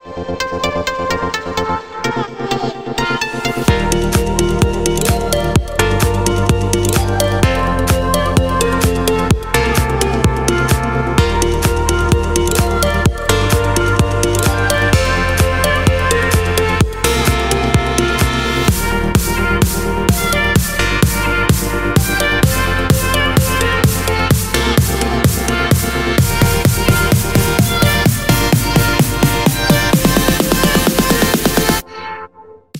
Dance - Electronics